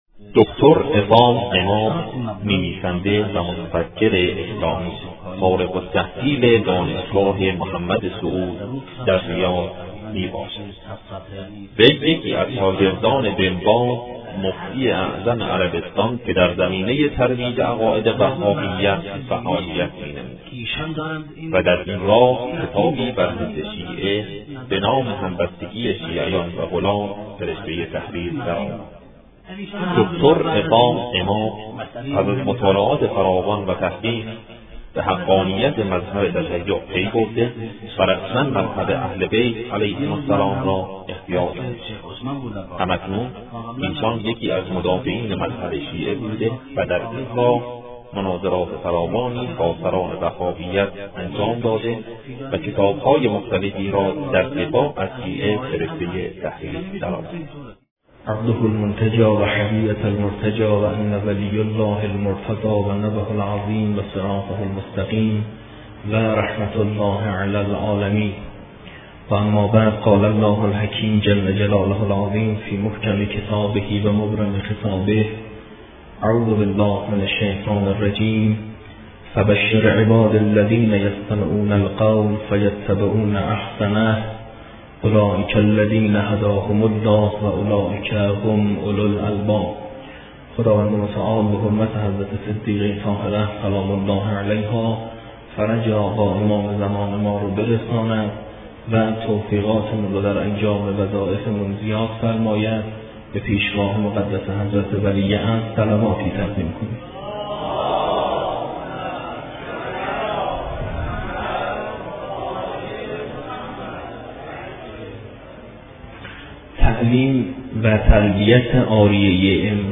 گفتگو-پیرامون-مذهب-شیعه---جلسه-4